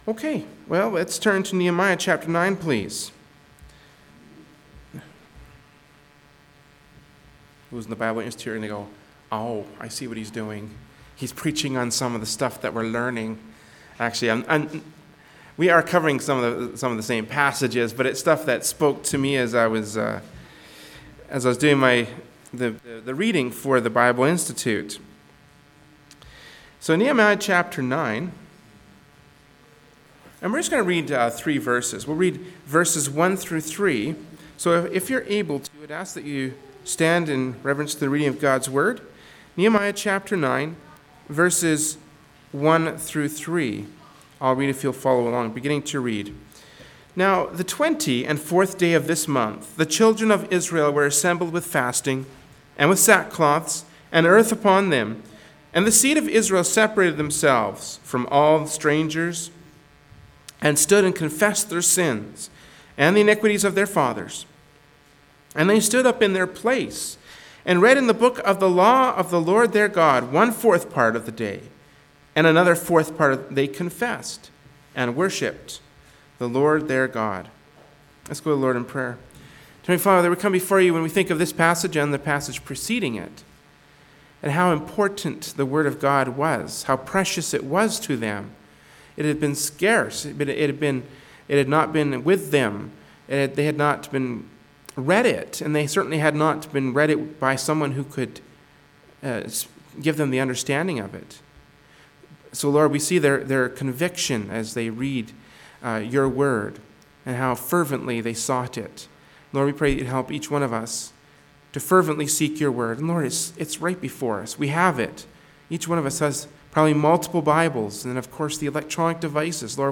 Genre: Preaching.
Passage: Nehemiah 9:1-3 Service Type: Wednesday Evening Service “Nehemiah 9:1-3” from Wednesday Evening Service by Berean Baptist Church.